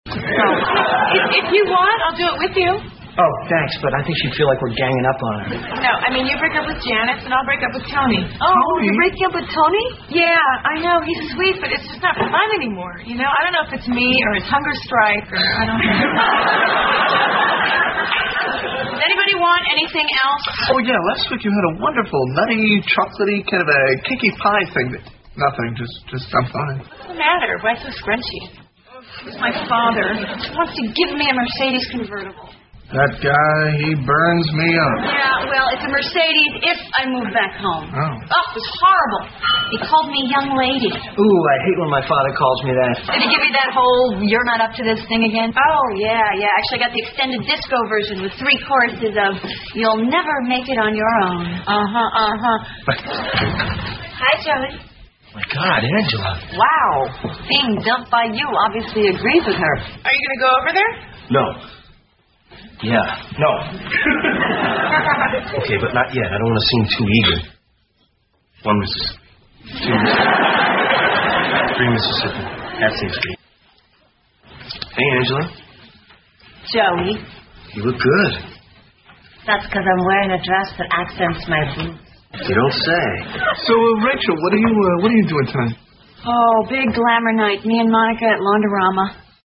在线英语听力室老友记精校版第1季 第49期:洗衣服(2)的听力文件下载, 《老友记精校版》是美国乃至全世界最受欢迎的情景喜剧，一共拍摄了10季，以其幽默的对白和与现实生活的贴近吸引了无数的观众，精校版栏目搭配高音质音频与同步双语字幕，是练习提升英语听力水平，积累英语知识的好帮手。